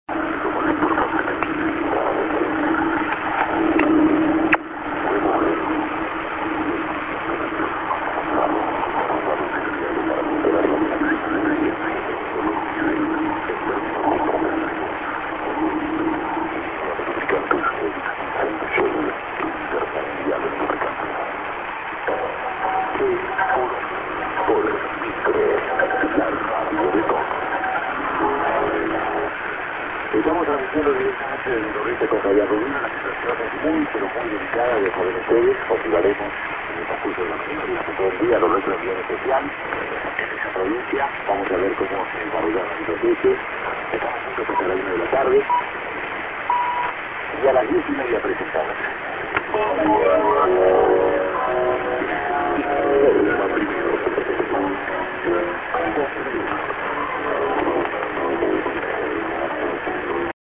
prog ->over the TS-> no ID